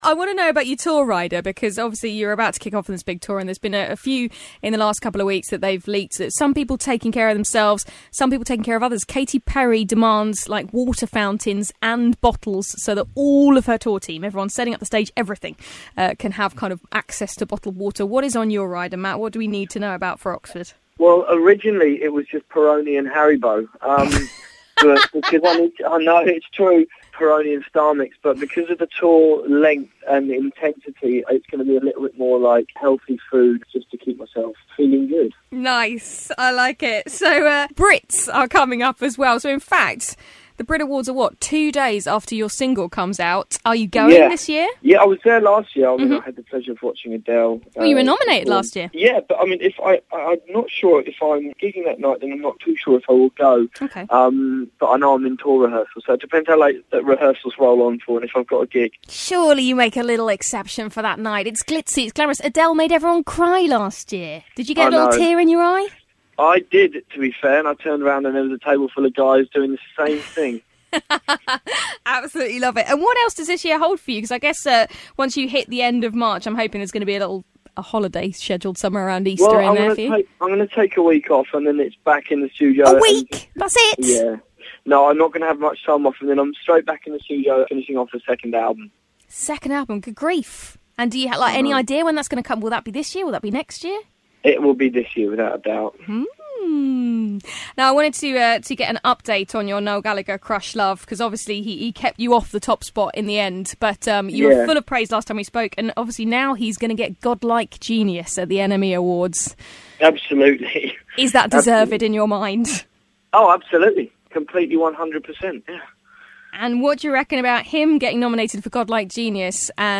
Glide's Morning Glory Interview Matt Cardle Part 2